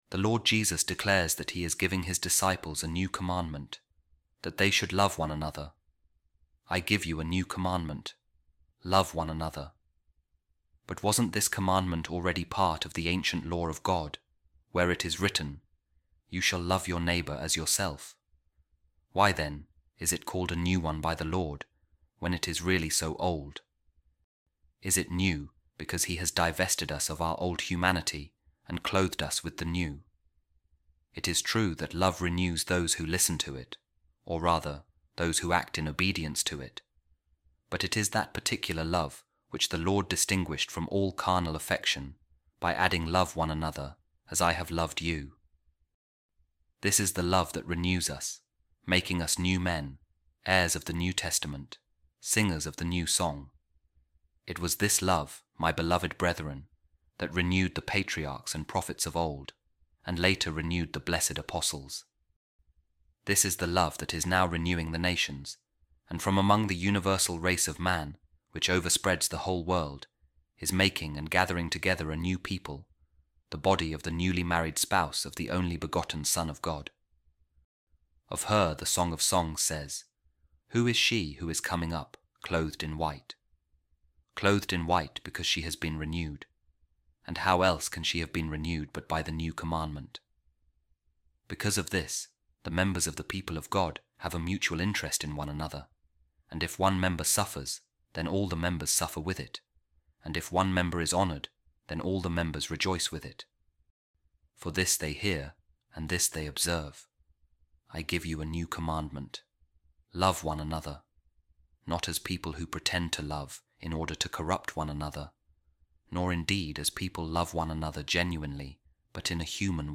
A Reading From The Homilies Of Saint Augustine On Saint John’s Gospel | Jesus And The New Commandment